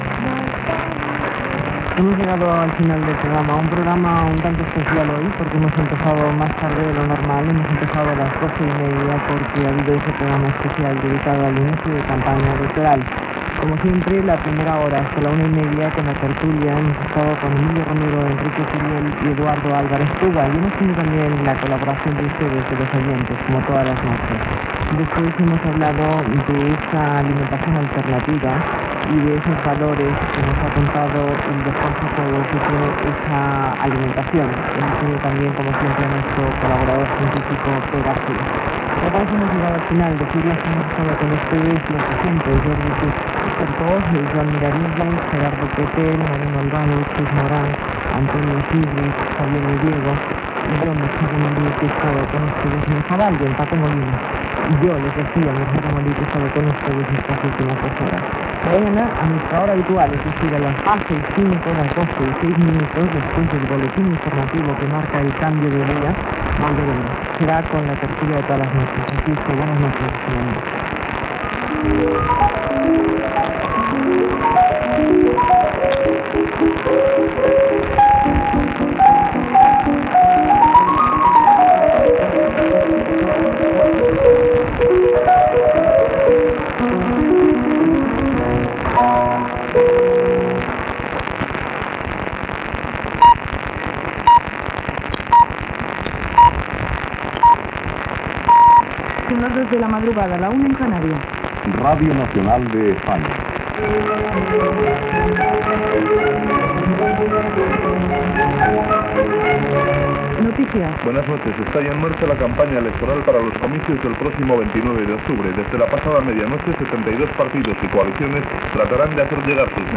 Informatiu
Qualitat de l'àudio defincient.